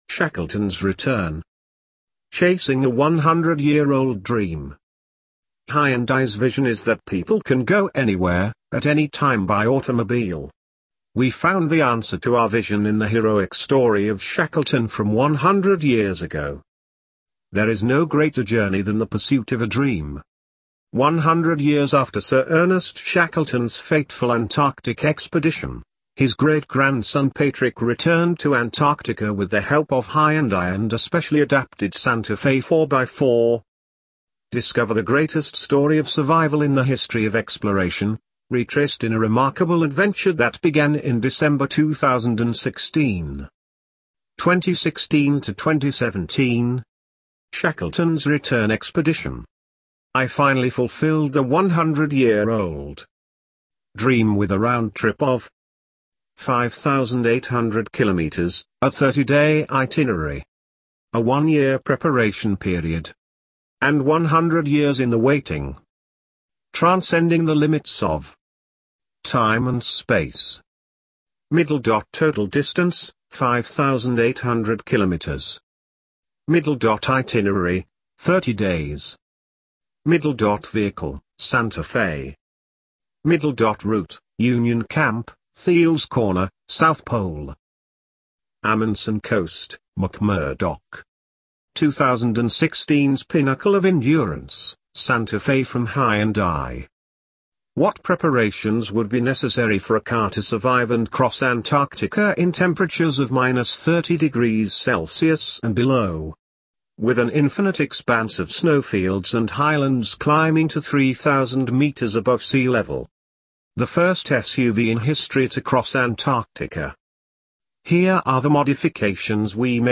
Shackleton's Return - TTS.mp3